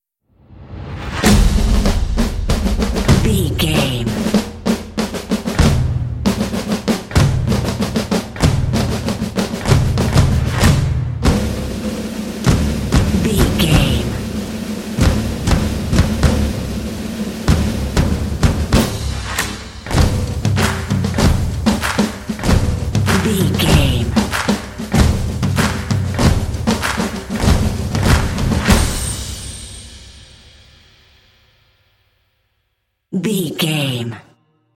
This epic drumline will pump you up for some intense action.
Epic / Action
Atonal
driving
motivational
epic
percussion
drums